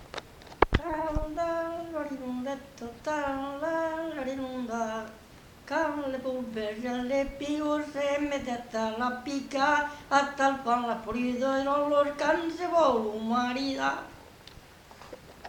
Genre : chant
Type de voix : voix d'homme
Production du son : chanté ; fredonné
Danse : polka piquée